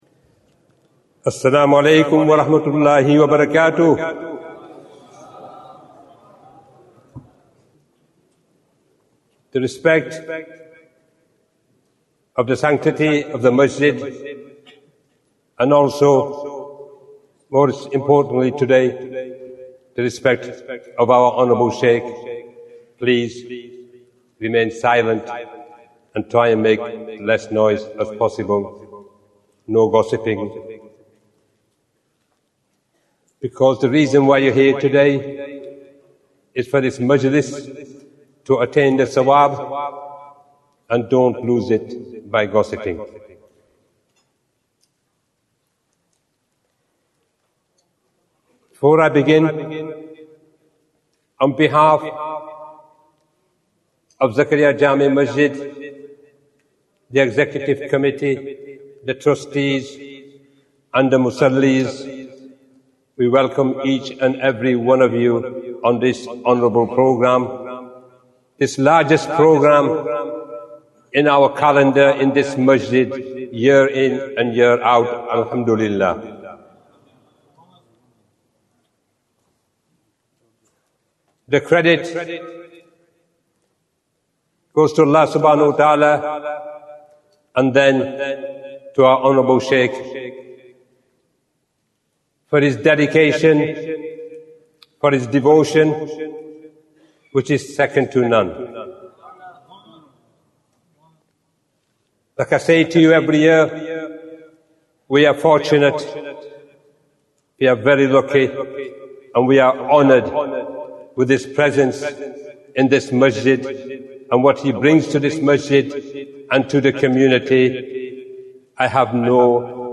Annual Ijtema Programme 2025 Bayan, 60 minutes25th December, 2025